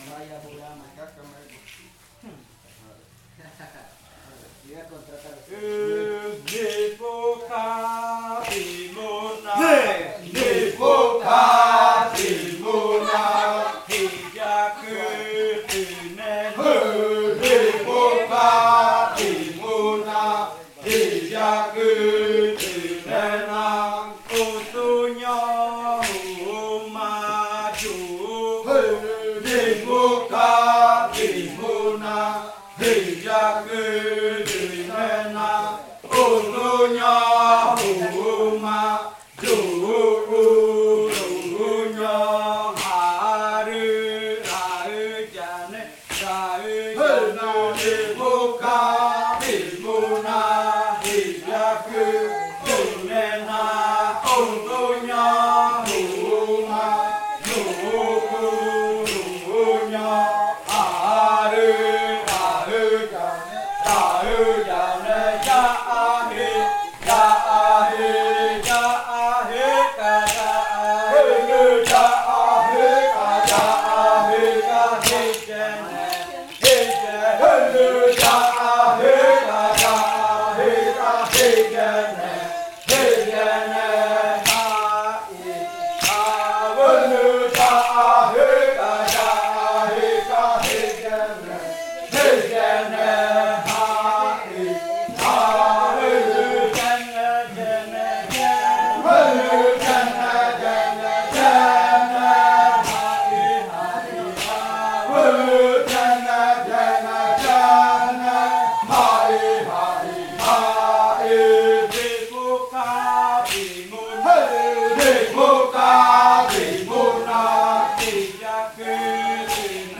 Canto de la variante muruikɨ
Leticia, Amazonas
con el grupo de cantores bailando en Nokaido.
with the group of singers dancing at Nokaido.